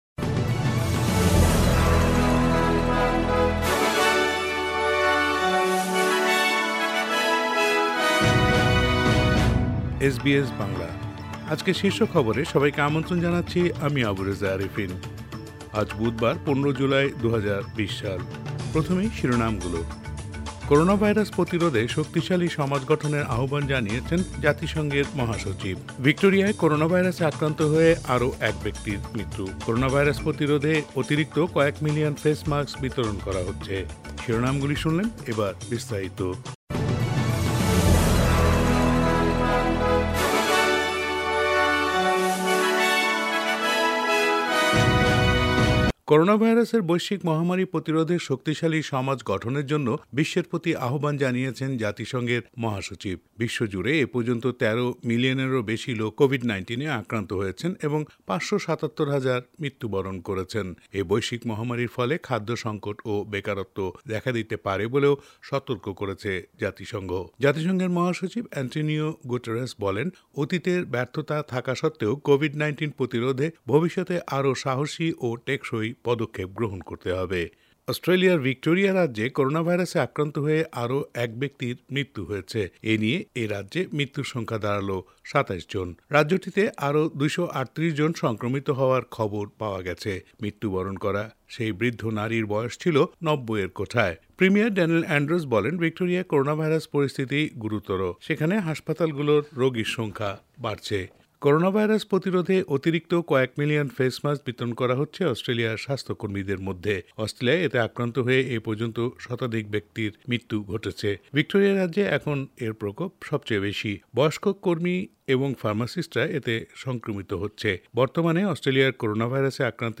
bangla_news_15_jul_mp3_final.mp3